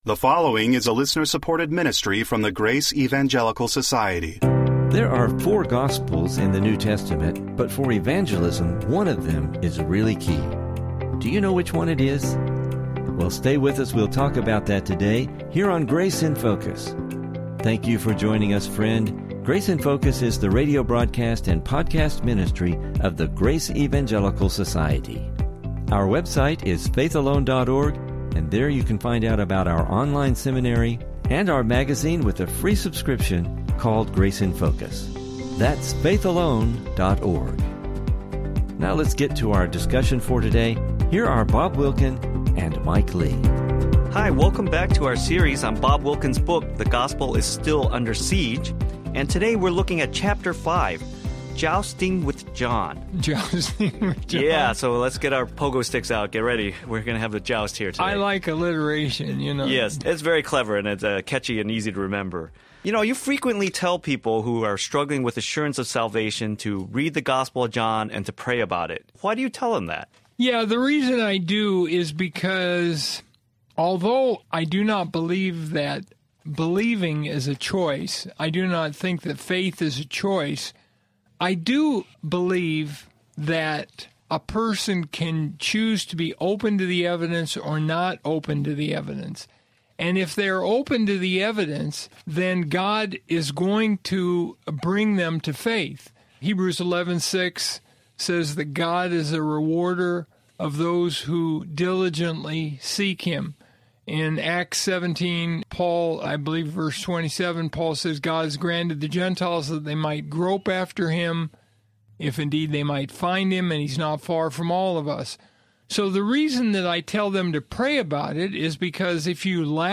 Please listen for a crucial Biblical discussion regarding this subject!